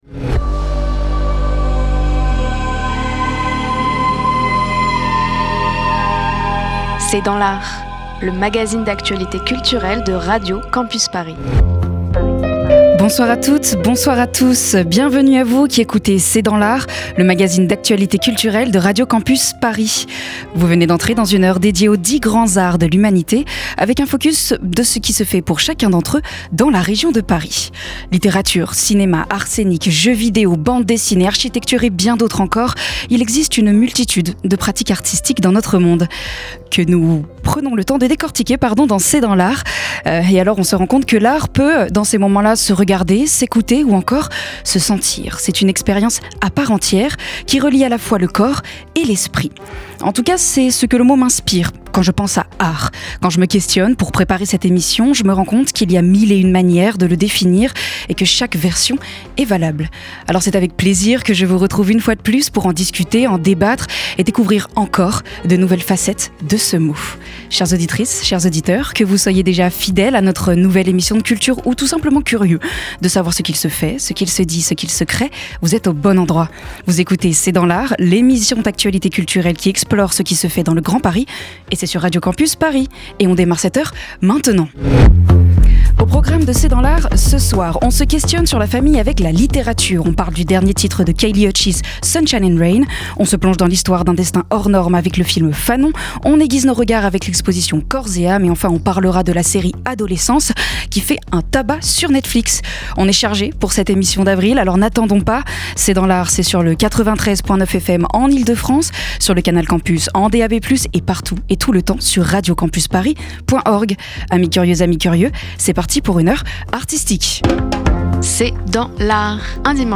C’est dans l’art, c’est l’émission d'actualité culturelle de Radio Campus Paris.